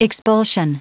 Prononciation du mot expulsion en anglais (fichier audio)
Prononciation du mot : expulsion